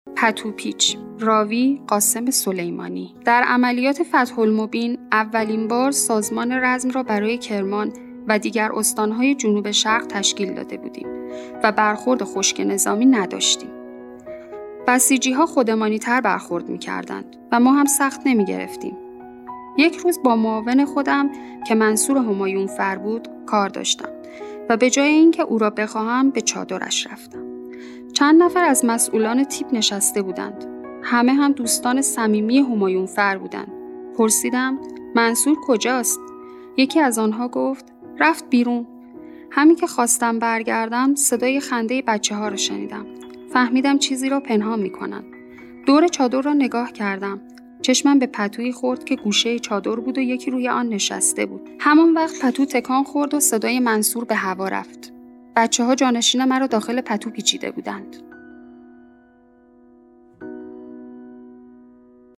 راوی: قاسم سلیمانی